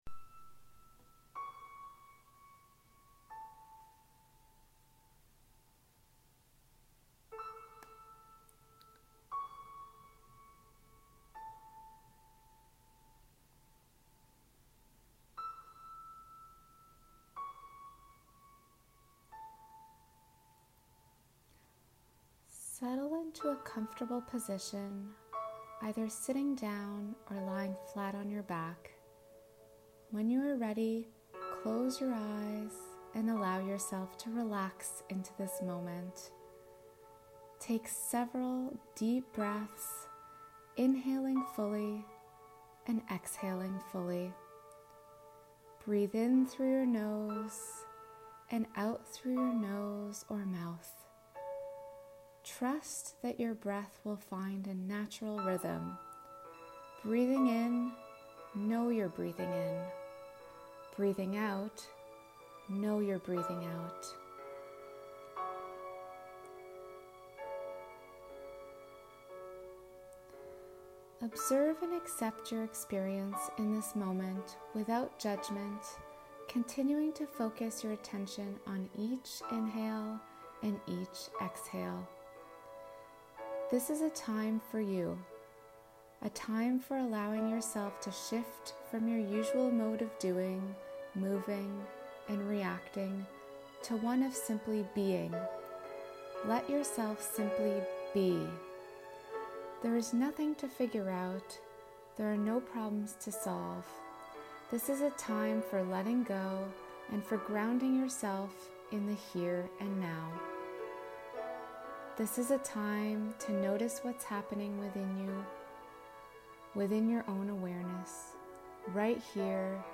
Mindfulness-Meditation-with-Music.mp3